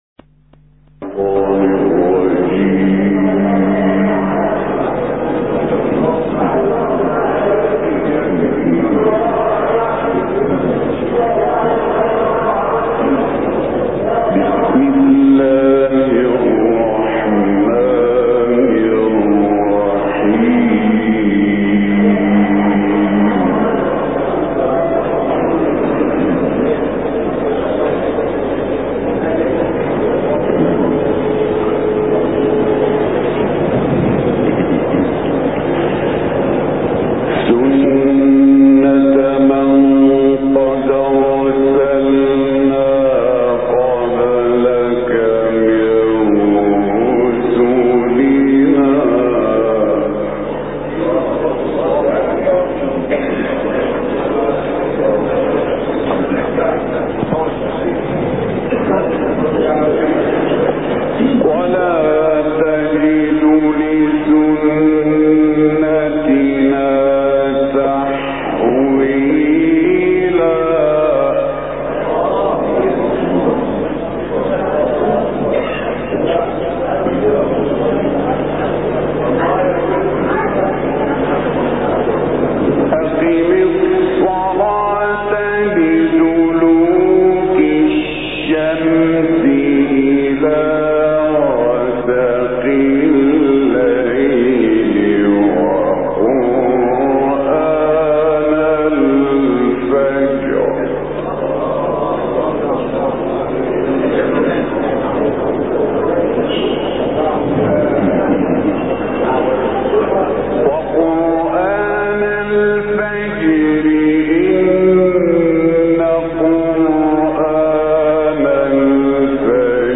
ملف صوتی ما تيسر من سورة الاسراء - 7 بصوت إبراهيم عبدالفتاح الشعشاعي